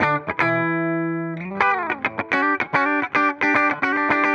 Index of /musicradar/dusty-funk-samples/Guitar/110bpm